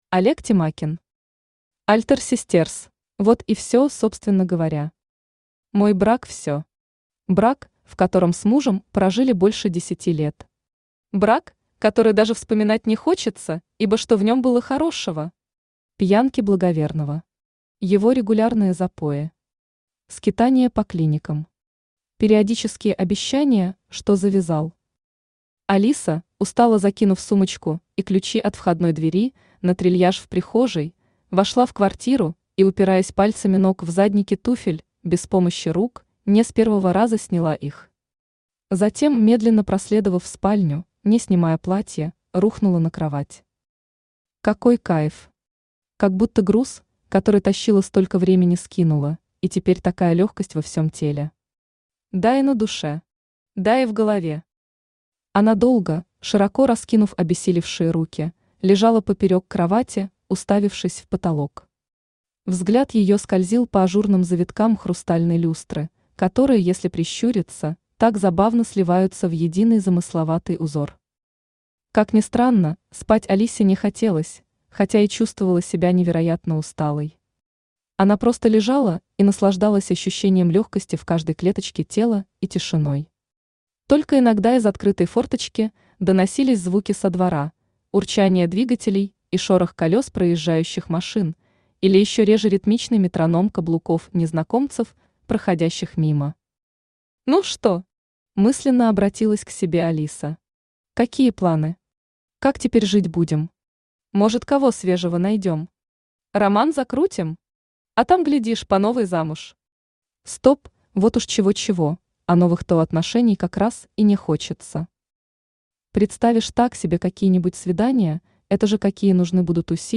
Аудиокнига Альтер систерз | Библиотека аудиокниг
Aудиокнига Альтер систерз Автор Олег Тимакин Читает аудиокнигу Авточтец ЛитРес.